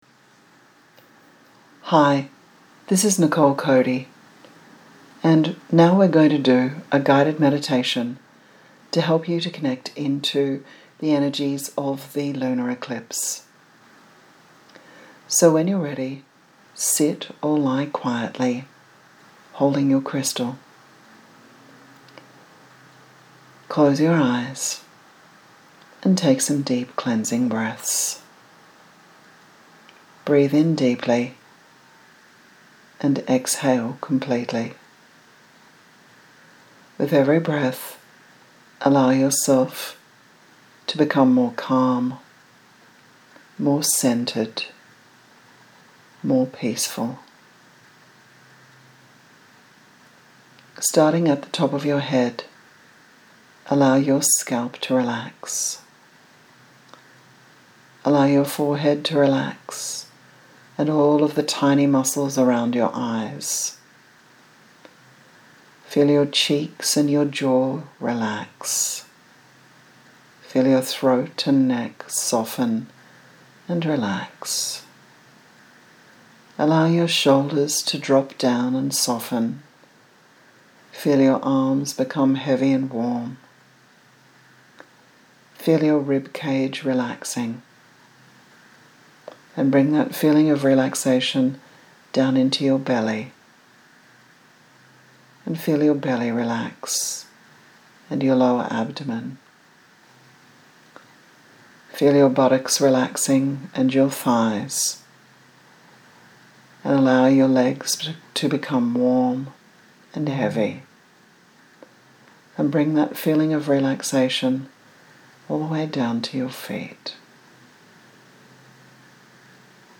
Free Guided Meditation for the January 2019 Supermoon Blood Moon Lunar Eclipse - Cauldrons and Cupcakes